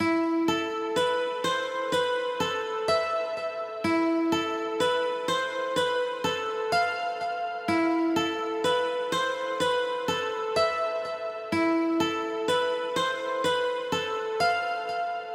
Tag: 125 bpm Trap Loops Guitar Acoustic Loops 2.58 MB wav Key : Unknown